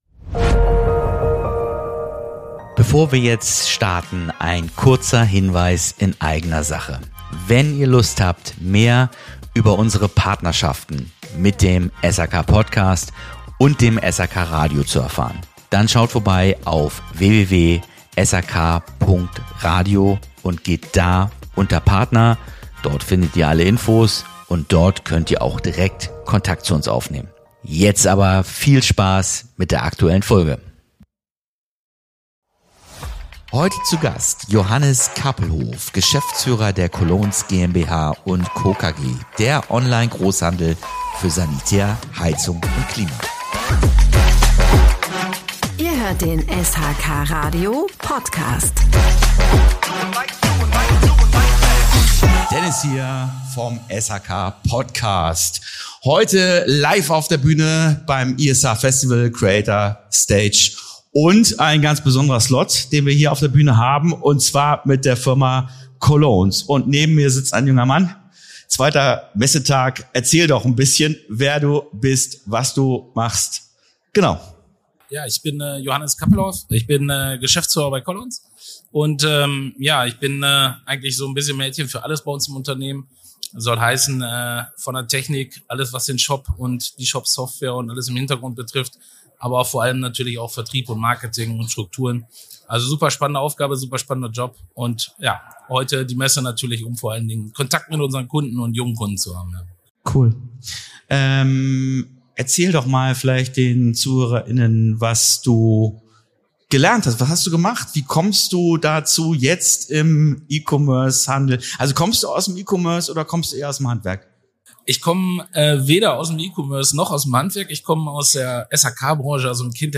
Die Folge wurde live auf dem ISH Festival 2025 auf der Creator Stage aufgezeichnet – und bietet einen spannenden Mix aus Persönlichkeit, Branchenblick und digitalem Fortschritt.